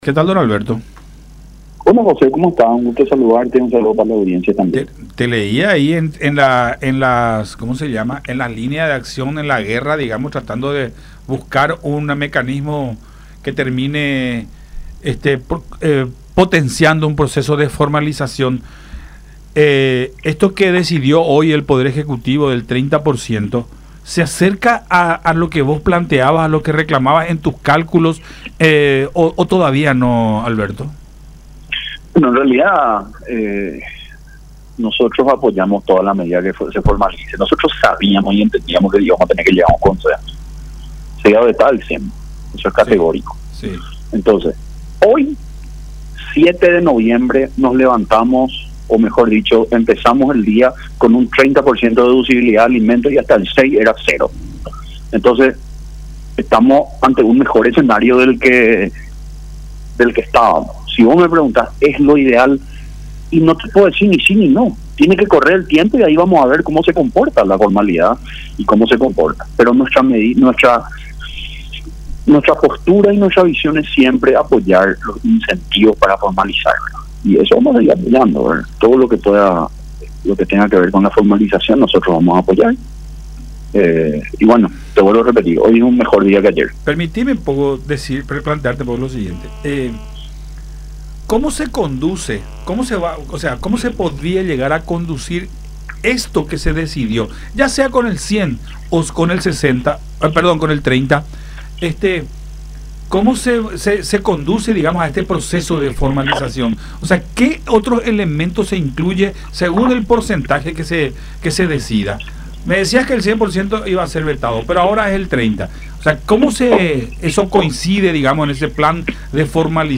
en charla con Buenas Tardes La Unión por Unión TV y radio La Unión.